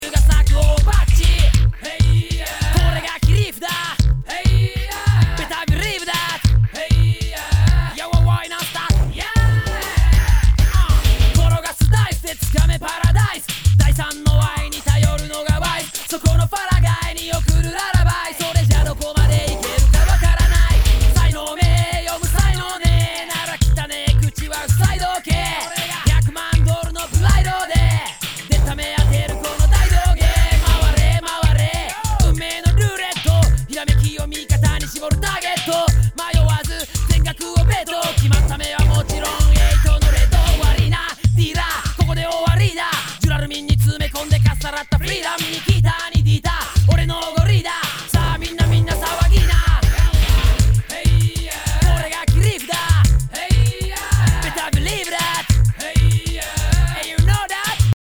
類別 雷鬼
和モノ/JAPANEASE GROOVE
ナイス！Jレゲエ！